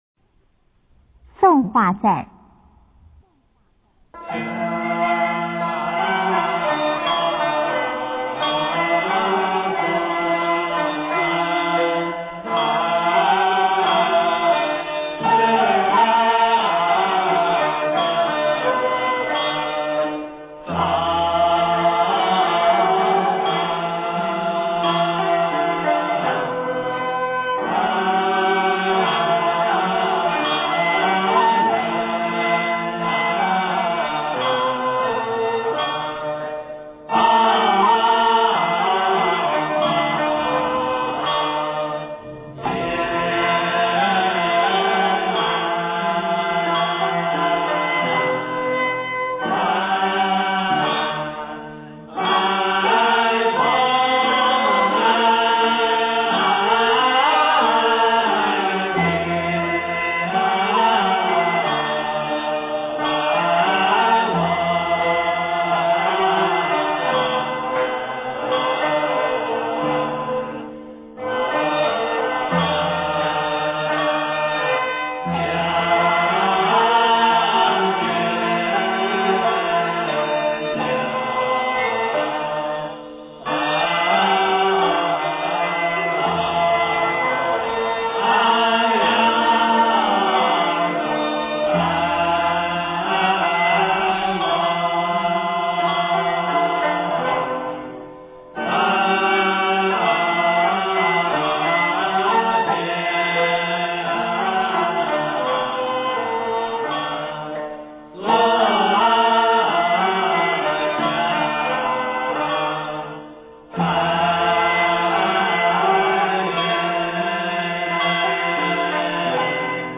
中国道教音乐 全真正韵 送化赞（香花送）